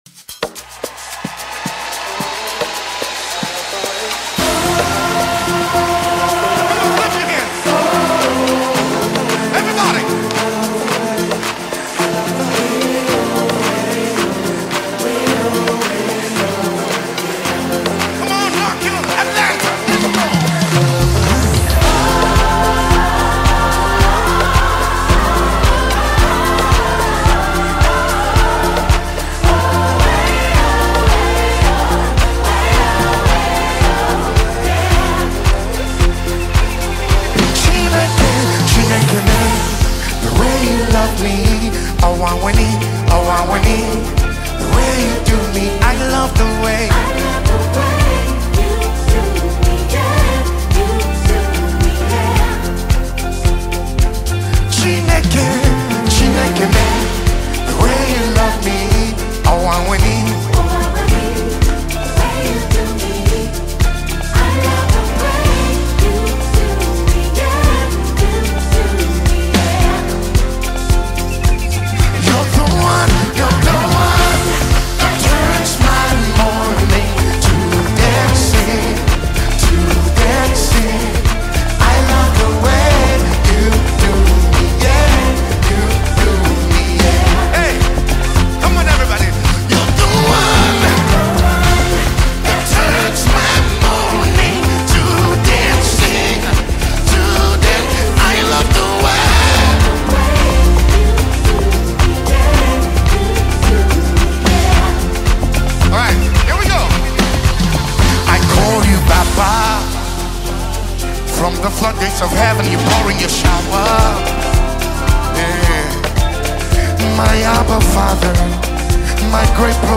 Ghanaian Gospel Music
Genre: Gospel/Christian